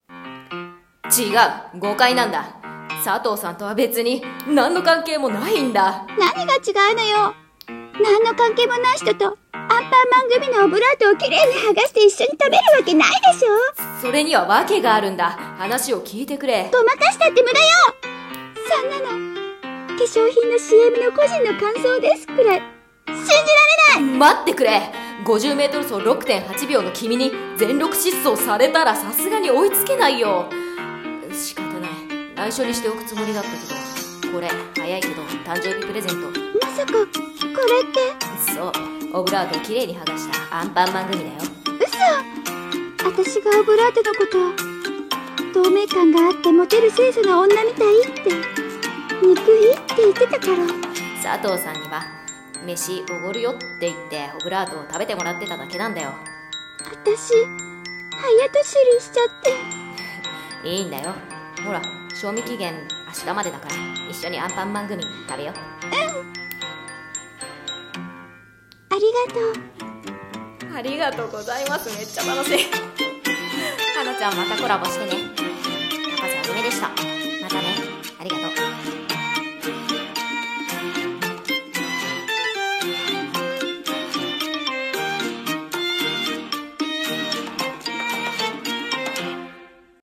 声劇『 なんか違うカップル